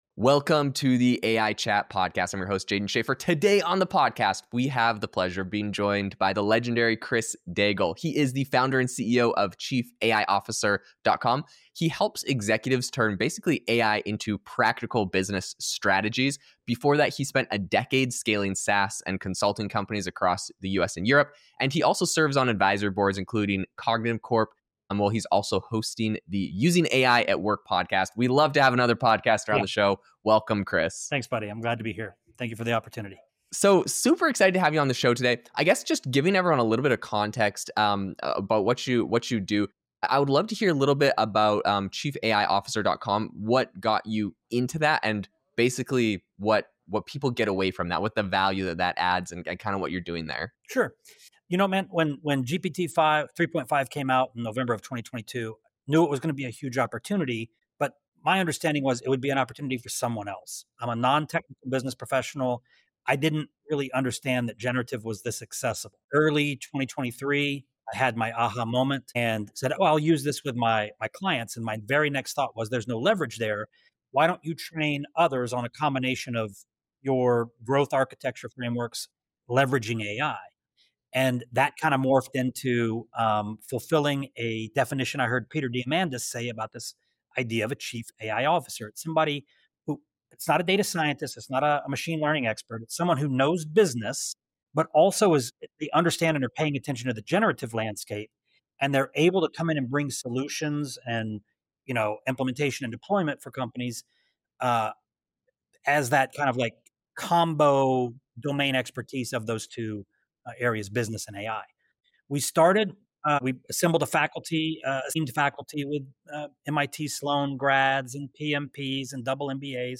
Format: Podcast interview